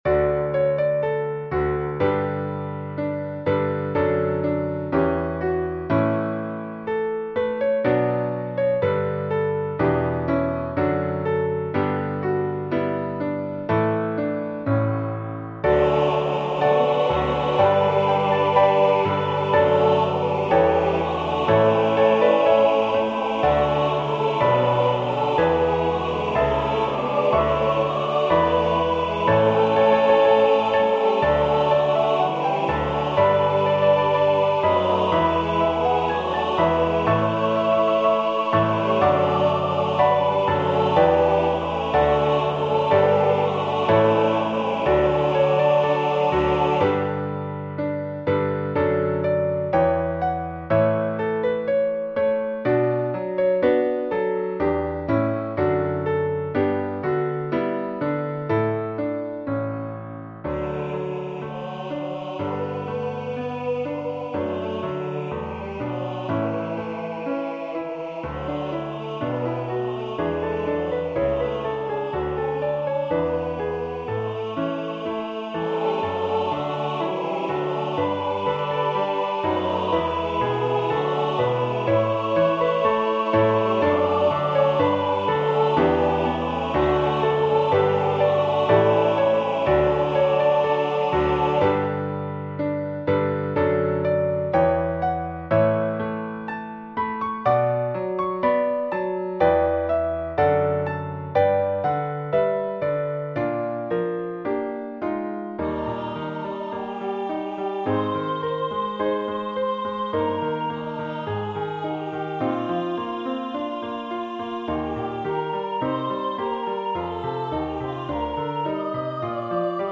Let Easter Anthems Ring Hymn #1205 SATB with Piano Accompaniment. It is a hymplicity-style arrangement meaning the SATB parts are straight out of the hymnbook.
Voicing/Instrumentation: SATB